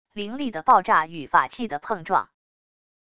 灵力的爆炸与法器的碰撞wav音频